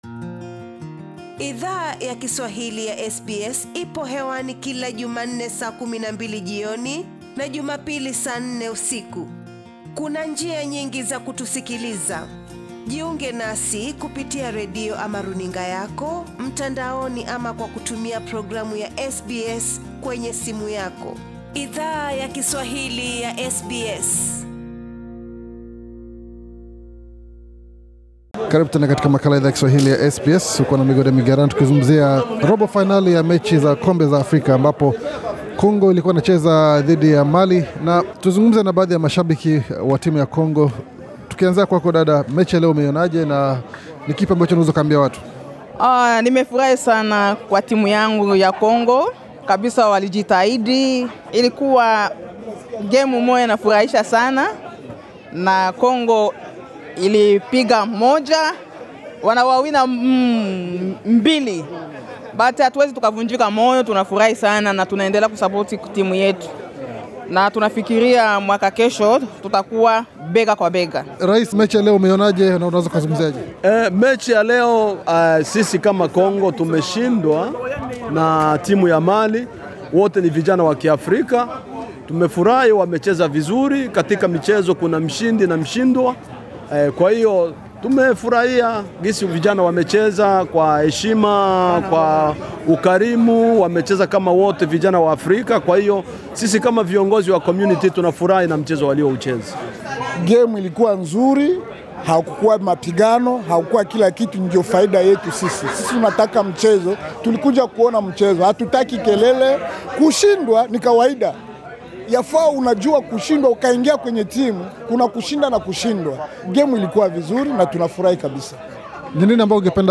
Hata hivyo, vijana wa timu ya Mali walikuwa na mipango tofauti kama vile Idhaa ya Kiswahili ya SBS ilivyo gundua tulipo hudhuria mechi hiyo, nakuzungumza na mashabiki na wachezaji wa DR Congo punde baada ya mechi hiyo kukamilika.